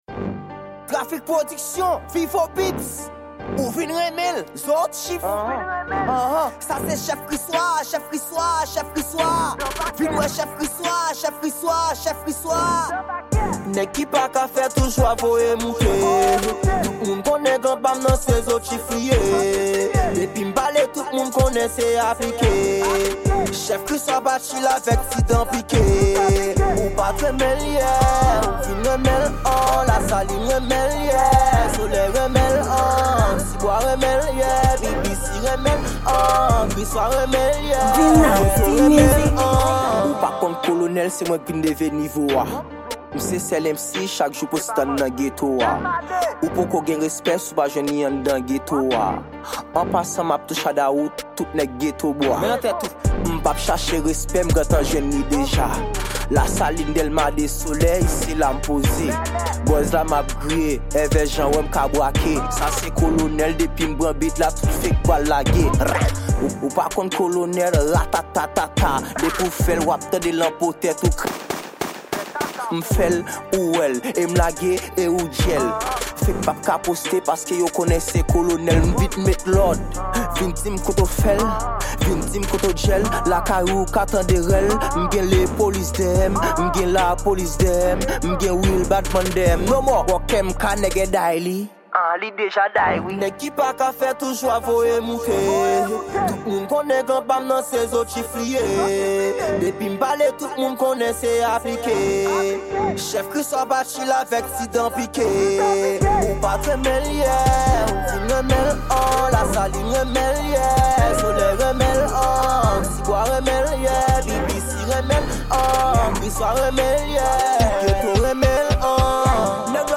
Genre: TraP